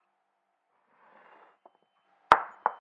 杯子滑动
描述：一个物体穿过木制柜台
Tag: 木材 玻璃 摩擦 移动 摩擦 OWI